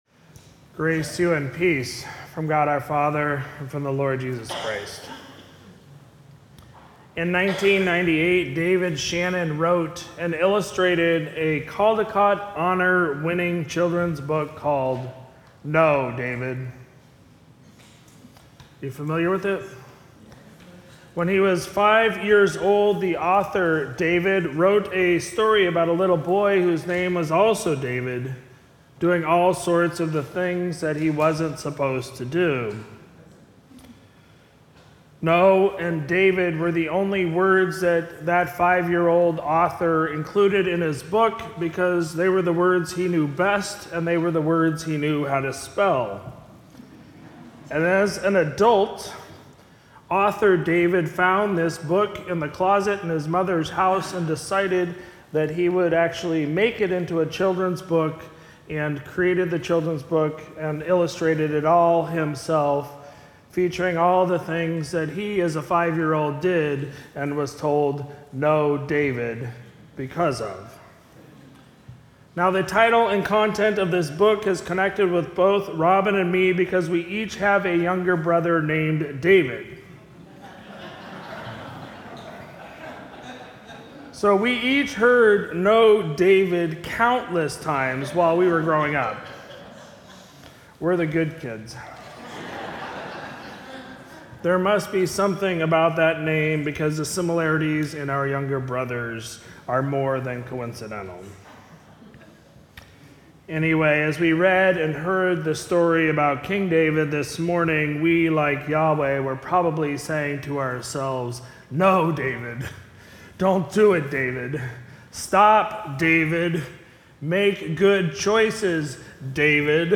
Sermon for Sunday, October 23, 2022